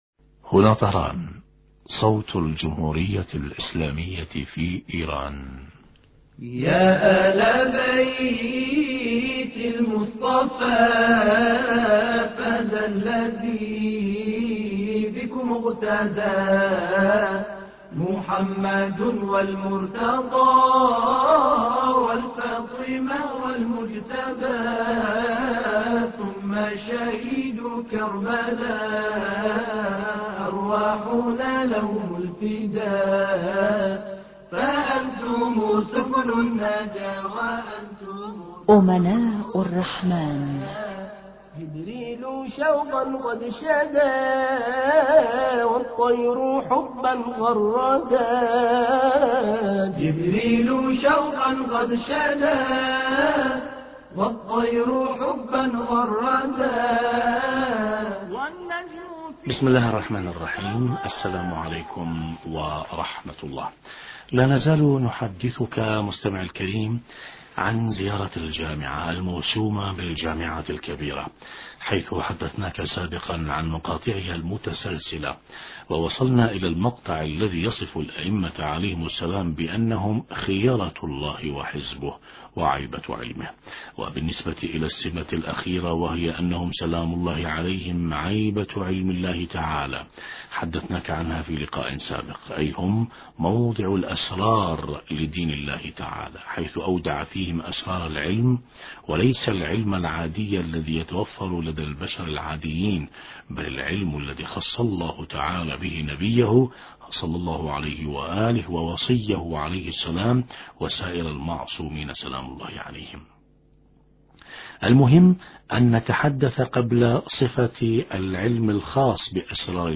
نتابع تقديم برنامج امناء الرحمن بهذا الاتصال الهاتفي